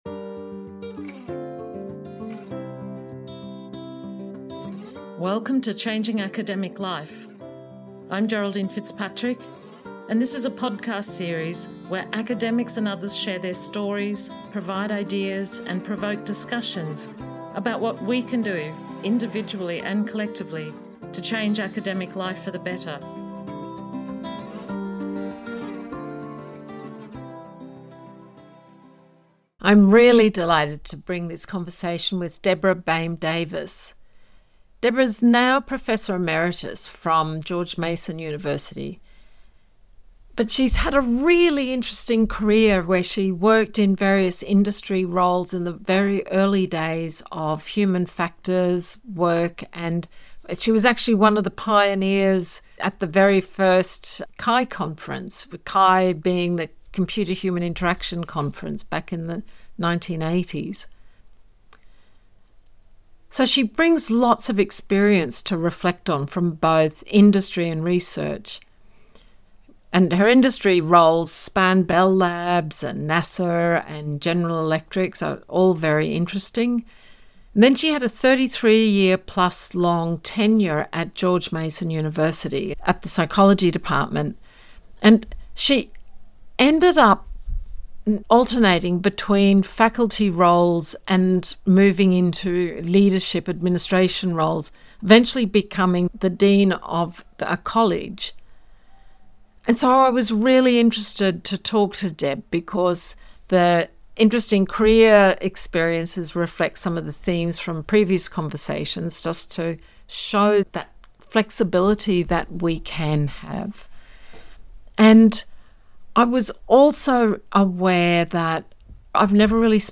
In Part 1 of our conversation, she describes her own sabbatical experience. She talks about how earning tenure in 2022 after the pandemic, young children, and family losses left her burned out and questioning her work’s impact.